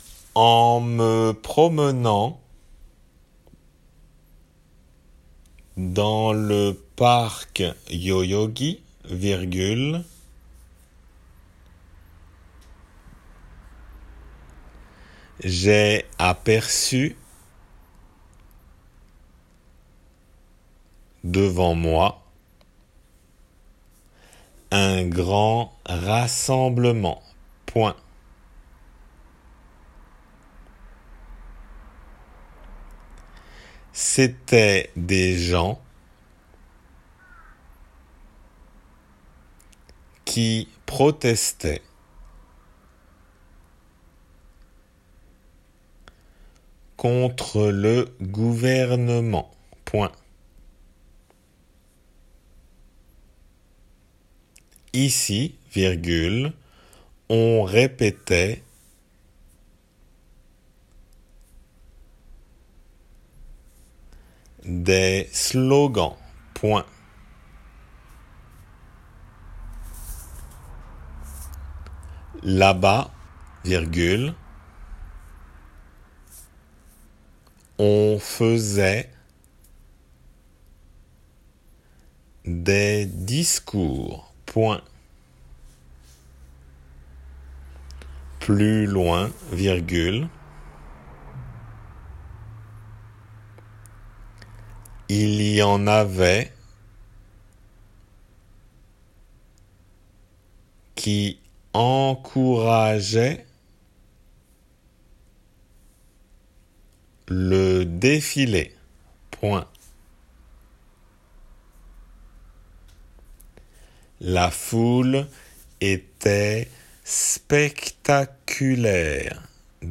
デイクテの速さで